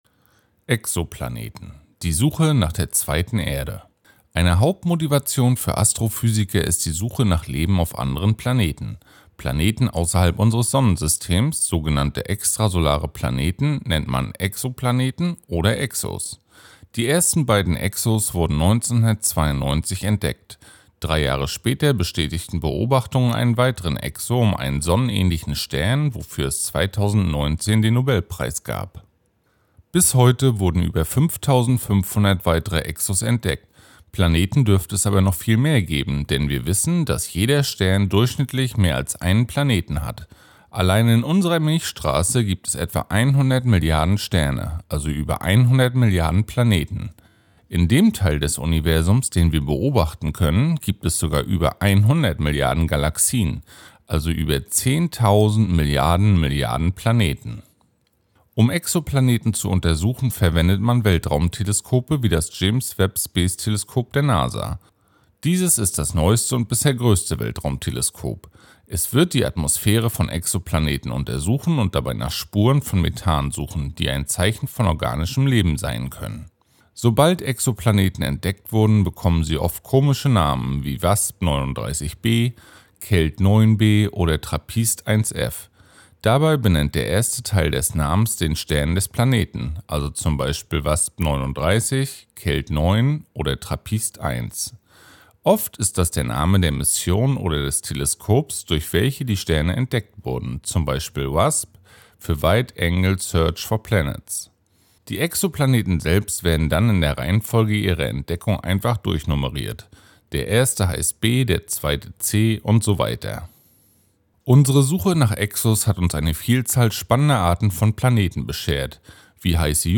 Audioguide
Promovierende vom Institut für Physik der Uni Rostock geben spannende Einblicke in ihre Forschung und erklären das Weltall.